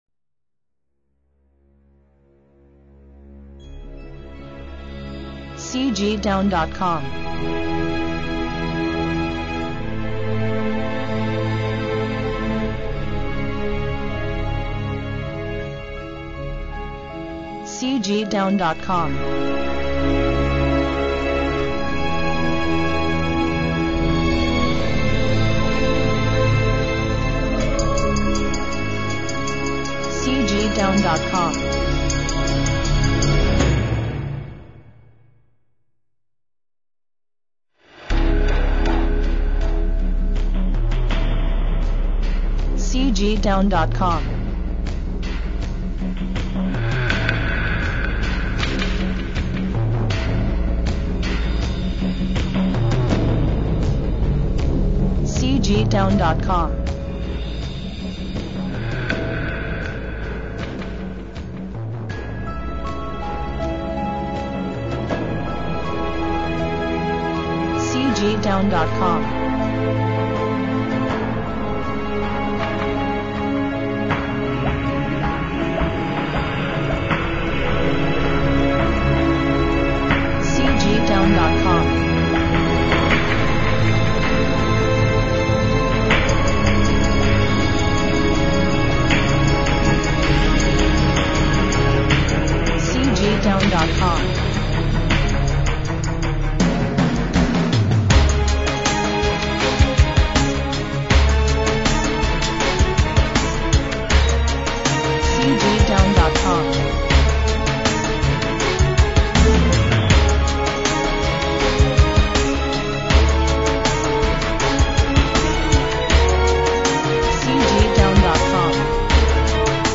悬疑 神秘